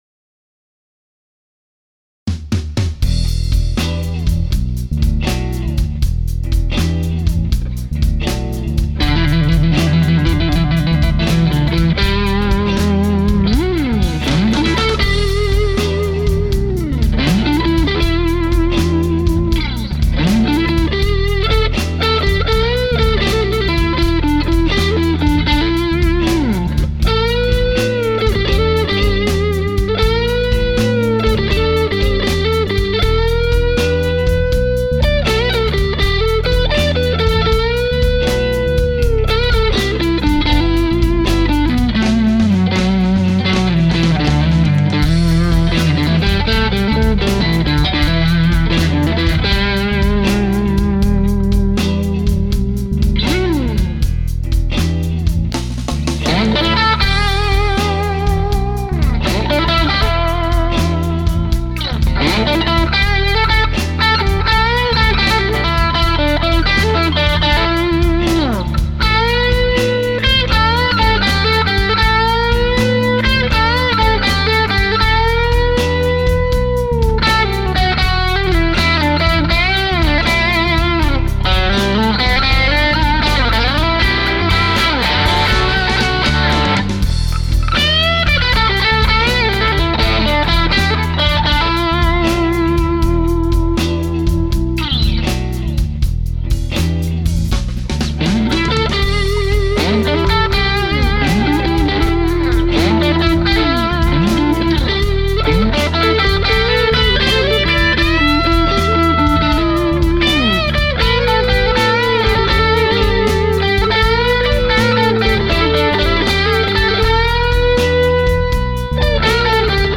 The note definition and separation is incredible with the Timmy.
The guitar parts have the bite I want, and the Timmy just adds tons of balls to my tone.
The first guitar part is played in the neck pickup. I love that pickup on this guitar as it has the real vowelly-like tone. The second part is in the bridge pickup that has a lovely drive and a Les Paul-like honk to it.
For that song, I set the pedal with volume at about 11 am, gain at 1pm, bass at noon, and roll off just some of the highs with the treble at 9am.